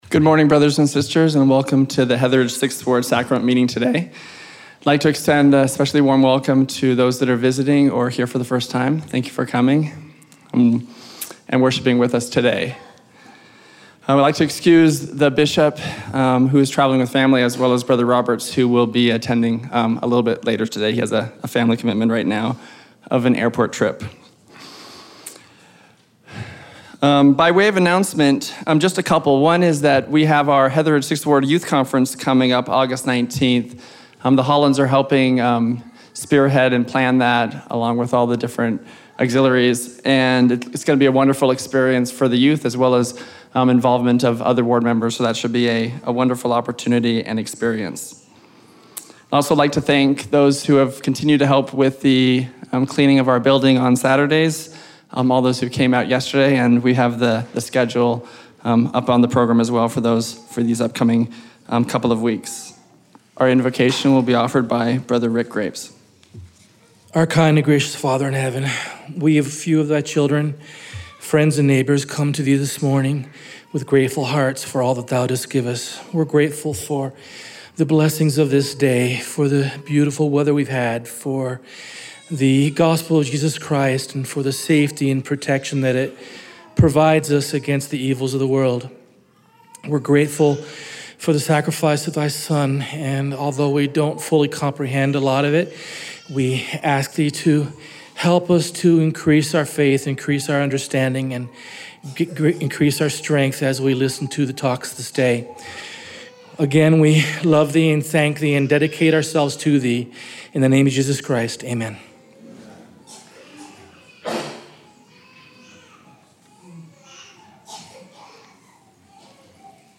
Heatheridge 6th Ward Sacrament Meeting